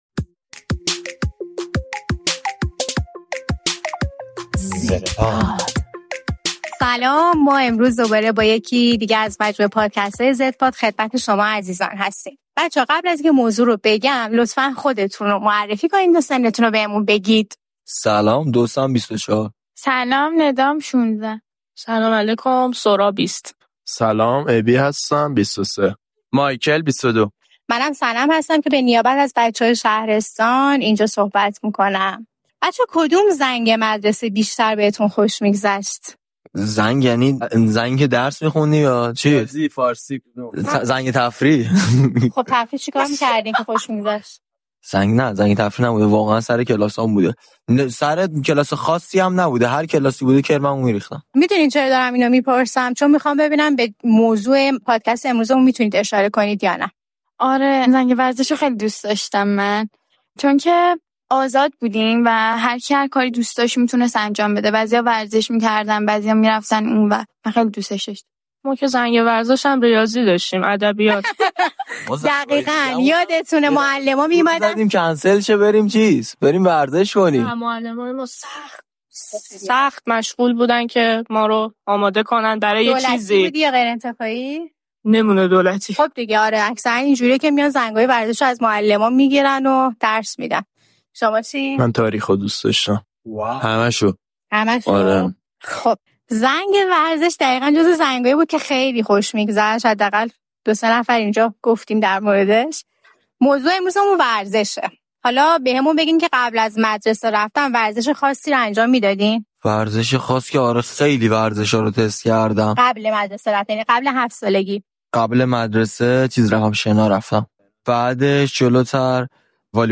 این قسمت زدپاد گفت‌وگو گروهی از بچه‌های نسل زد درباره رابطه آن‌ها با ورزش است. اینکه آیا ورزش نقش مهمی در زندگی این بچه‌ها دارد.